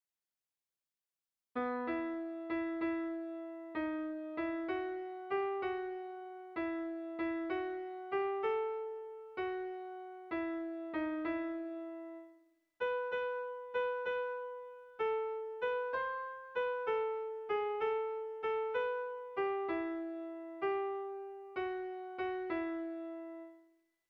Melodías de bertsos - Ver ficha   Más información sobre esta sección
Lauko handia (hg) / Bi puntuko handia (ip)